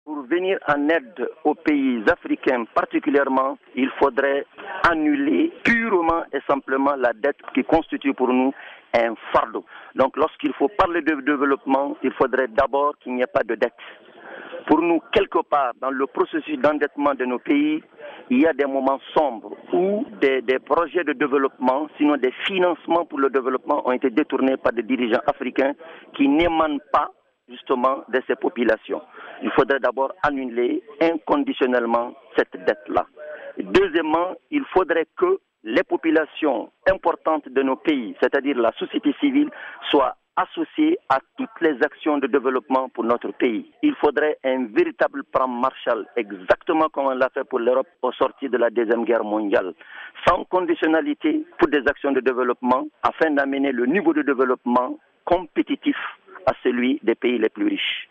Propos recueillis